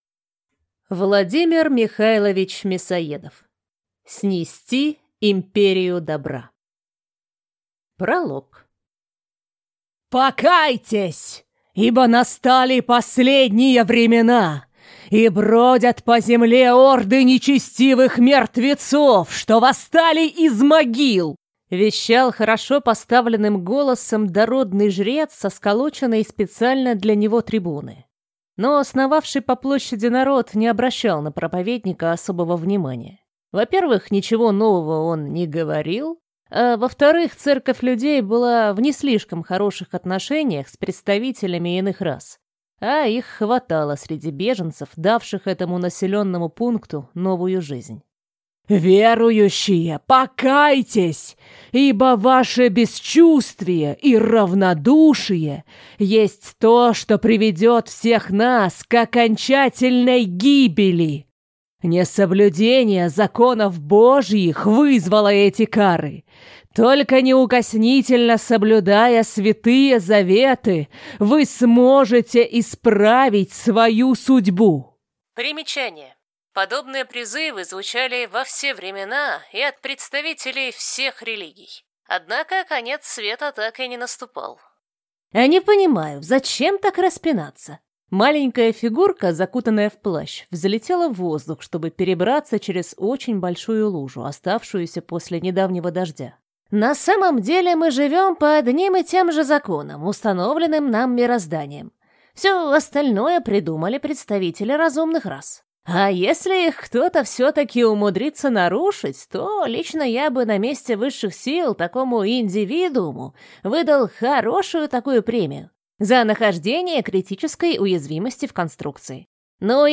Аудиокнига Снести империю добра | Библиотека аудиокниг